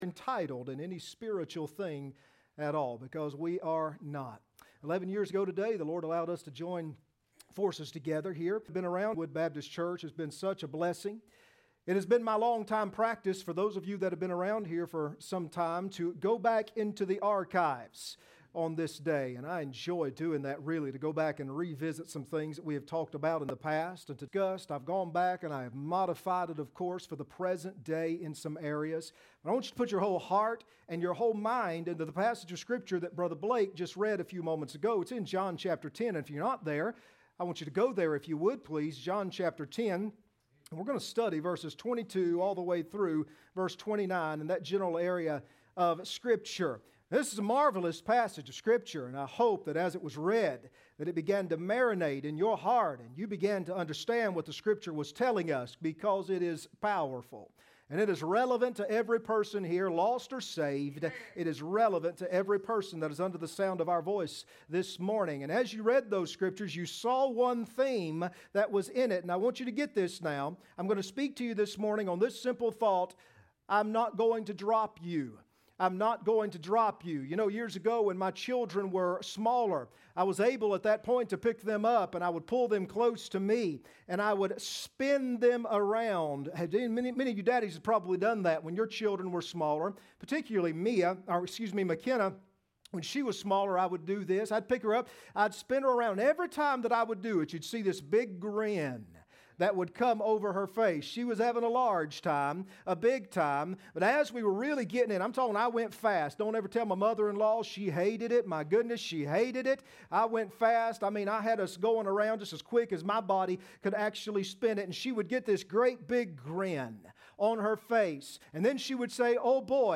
John 10:28-29 Service Type: Sunday Morning Next Sermon